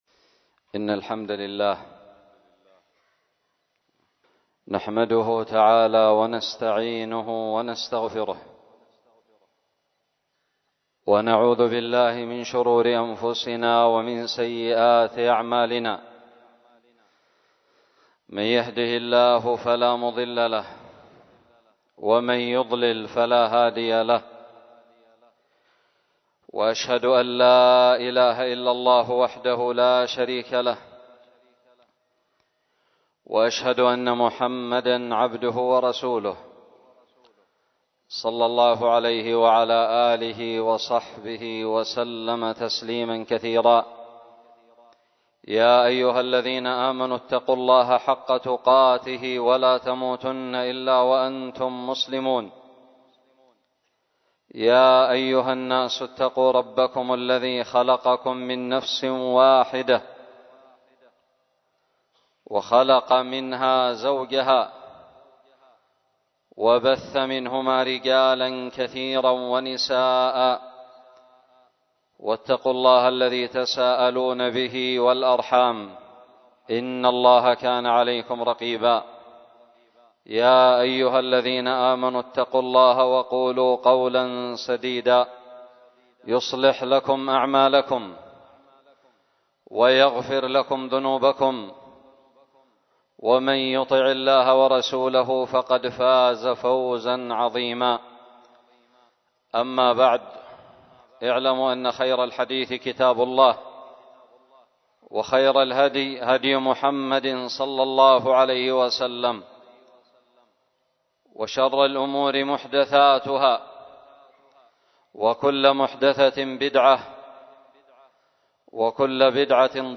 خطب الجمعة
ألقيت بدار الحديث السلفية للعلوم الشرعية بالضالع